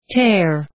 Προφορά
{teər}